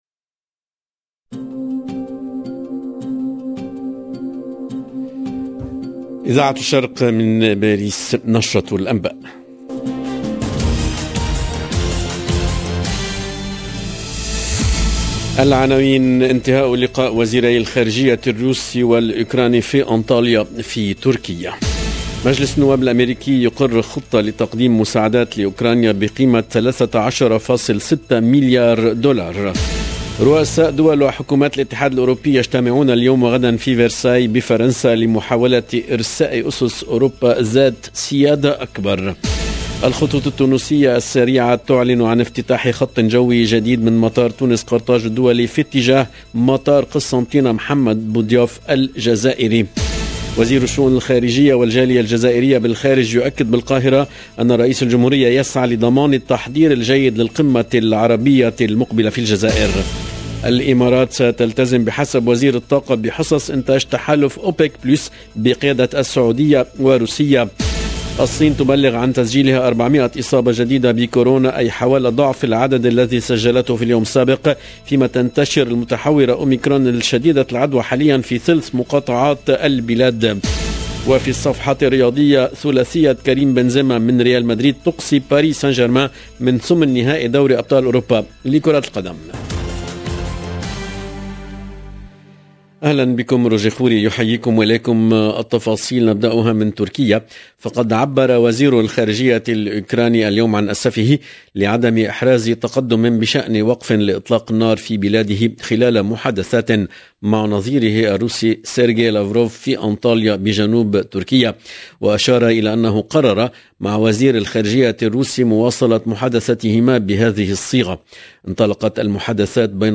EDITION DU JOURNAL DE 12H30 EN LANGUE ARABE DU 10/3/2022